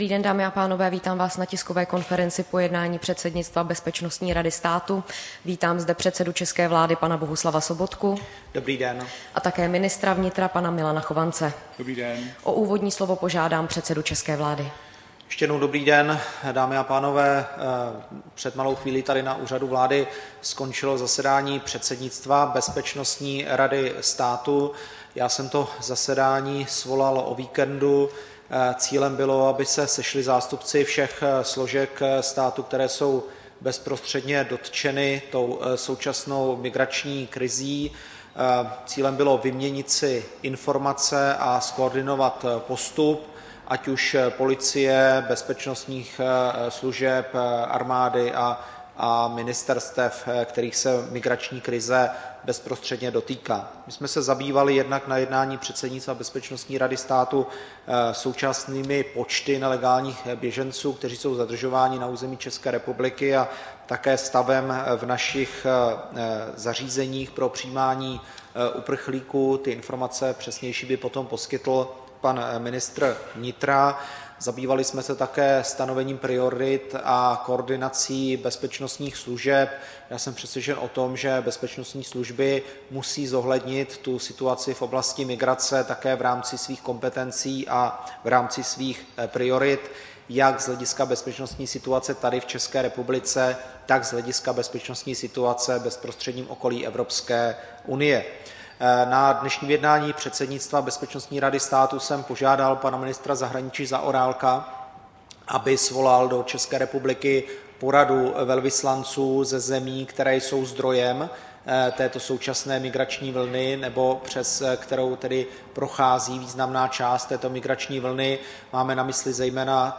Tisková konference po jednání předsednictva Bezpečnostní rady státu 10. září 2015